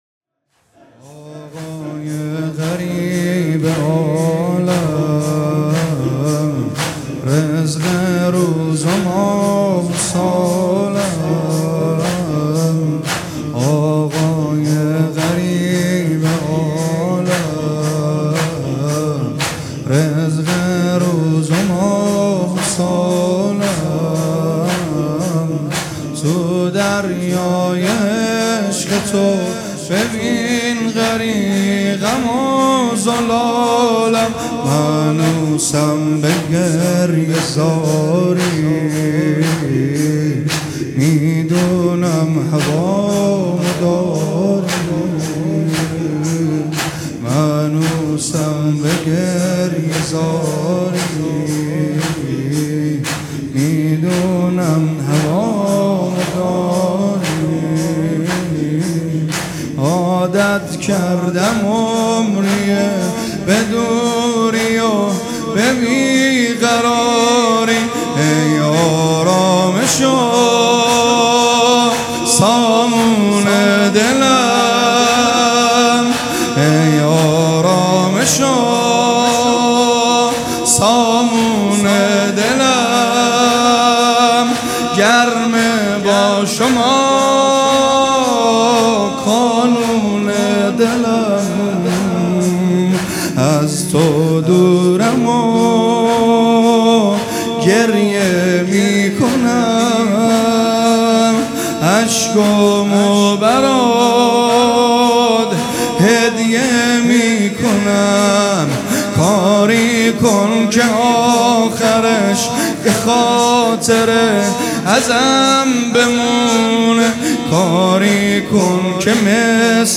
مراسم مناجات شب بیست و چهارم ماه رمضان
حسینیه ریحانة‌الحسین(س)